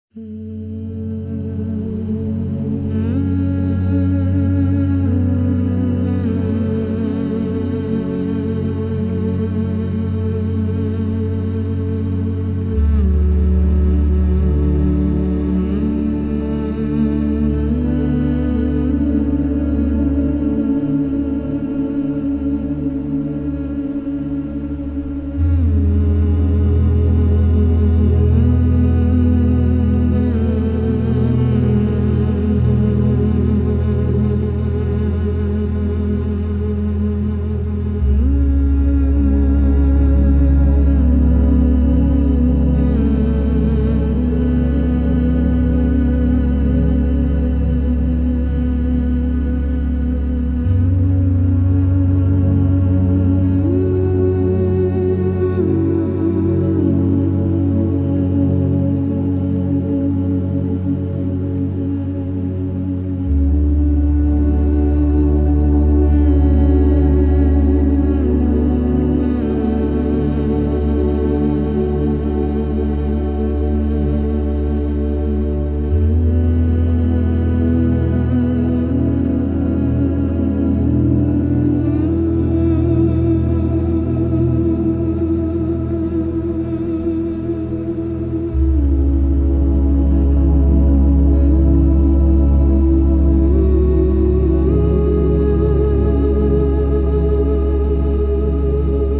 • Качество: 320, Stereo
спокойные
без слов
медленные
успокаивающие
спокойная музыка